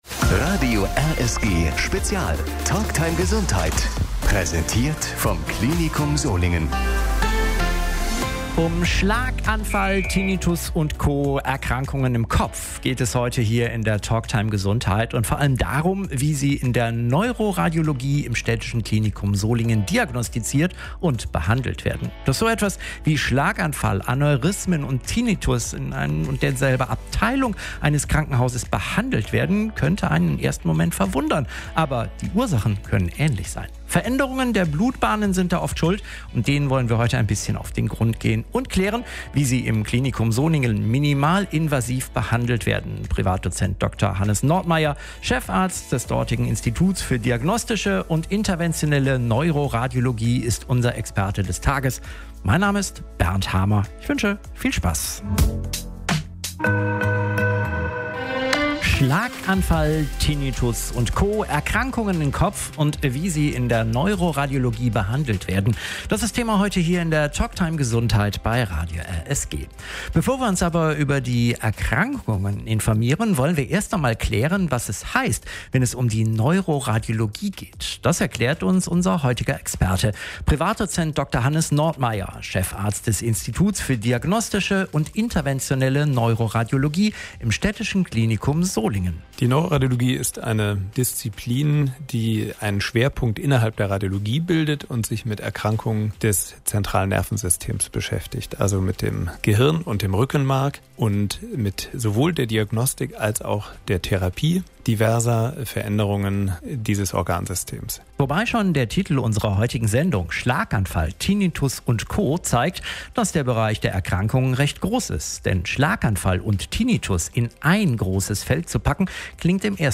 Die Sendung zum Nachhören